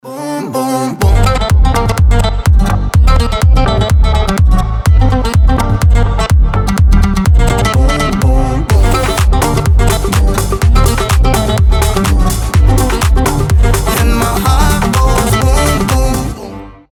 гитара
быстрые